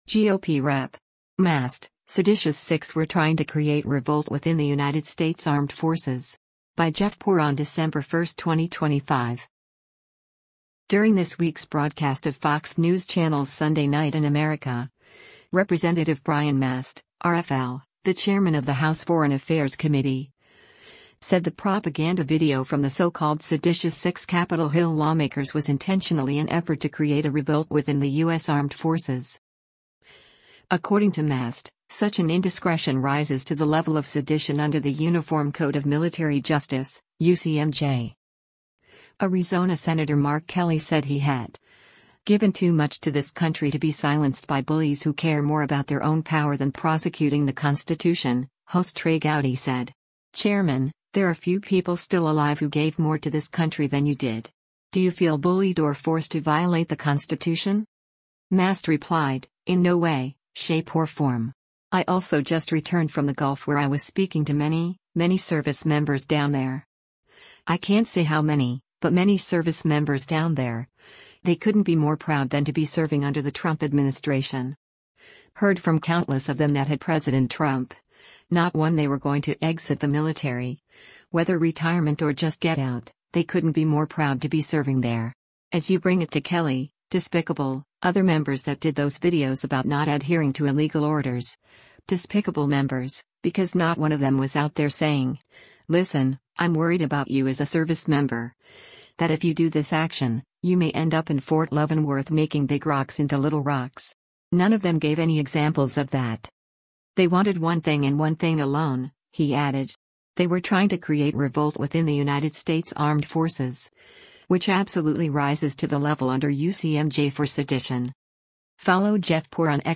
During this week’s broadcast of Fox News Channel’s “Sunday Night in America,” Rep. Brian Mast (R-FL), the chairman of the House Foreign Affairs Committee, said the propaganda video from the so-called “Seditious Six” Capitol Hill lawmakers was intentionally an effort to “create a revolt” within the U.S. Armed Forces.